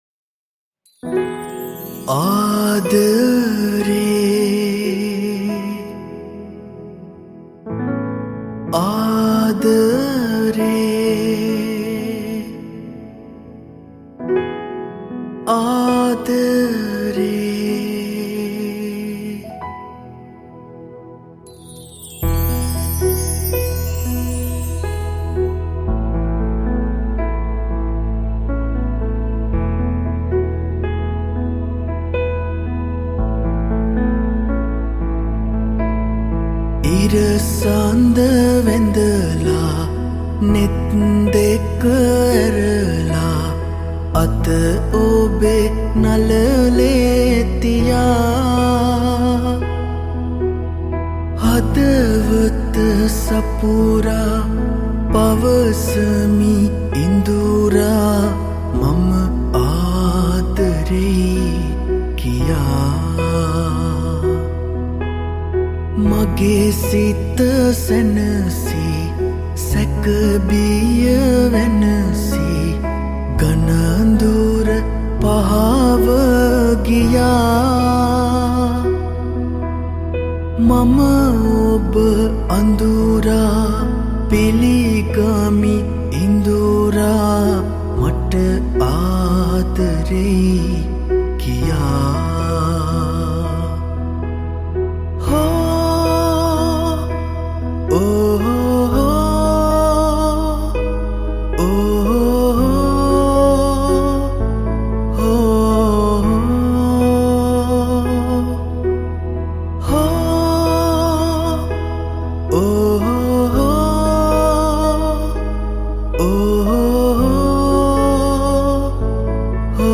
Cover Vocals